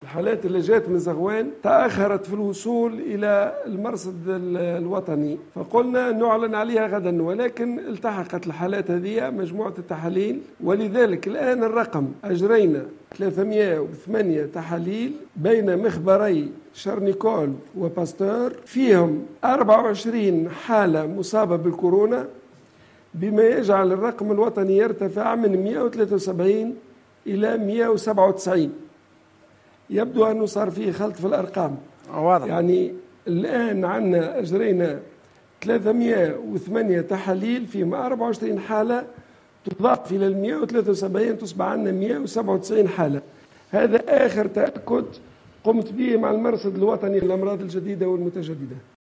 تم تسجيل 24 حالة اصابة جديدة بفيروس كورونا المستجد بتاريخ 25 مارس 2020 من مجموع 308 تحليل مخبري، ليصل العدد الجملي للحالات المؤكدة في تونس الى 197 اصابة، وفق ما أعلن عنه وزير الصحة عبد اللطيف المكي في تصريح لقناة قرطاج + ، اليوم الخميس.